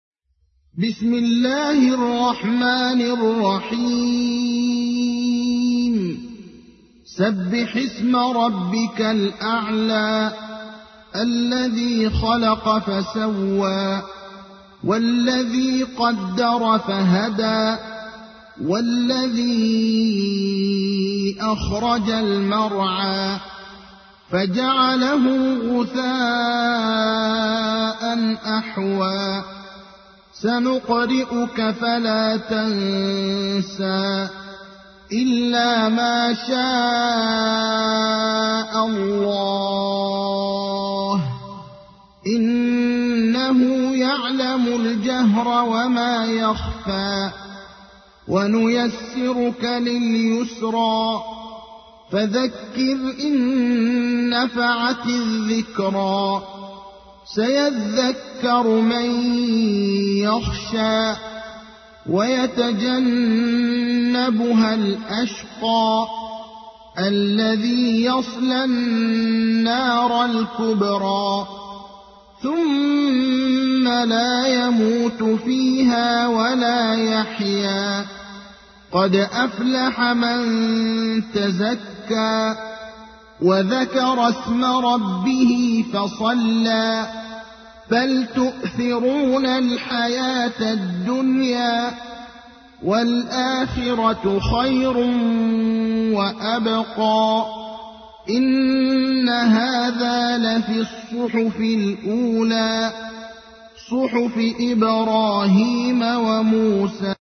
تحميل : 87. سورة الأعلى / القارئ ابراهيم الأخضر / القرآن الكريم / موقع يا حسين